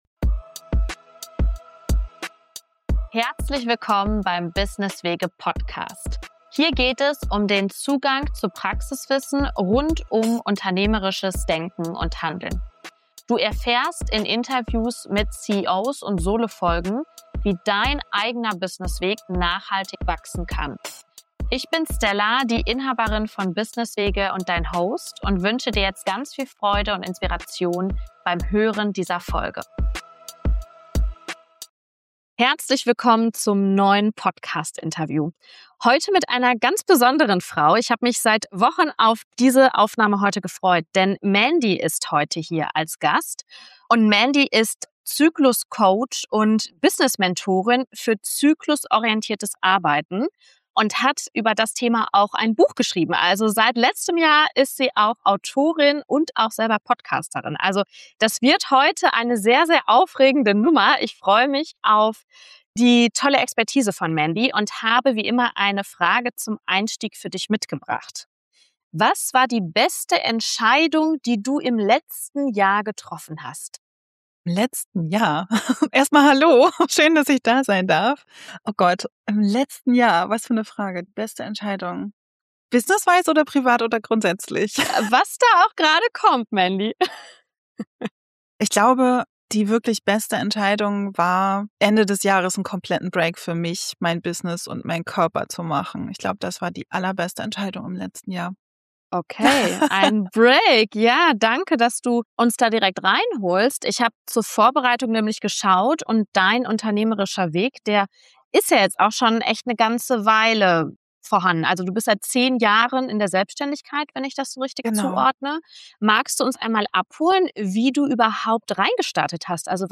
CEO-Talk